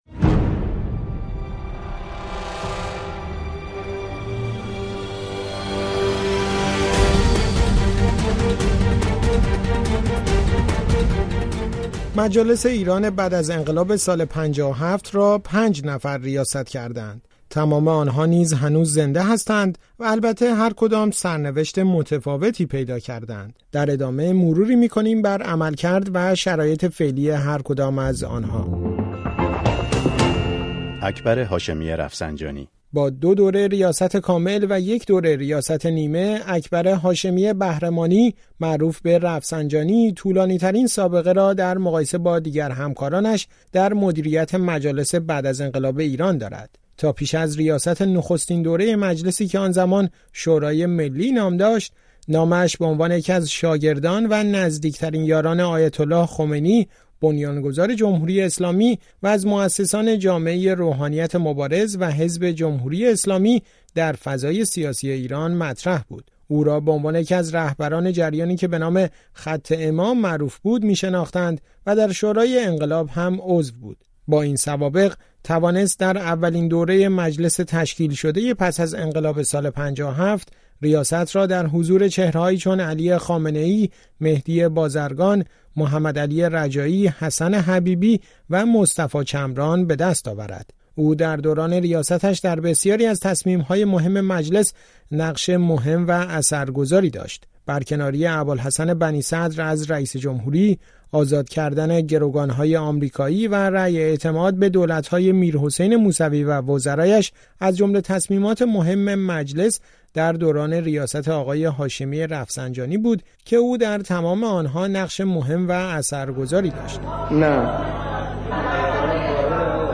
در این گزارش مروری می‌کنیم بر عملکرد و شرایط فعلی هرکدام از رؤسای بعد از انقلاب.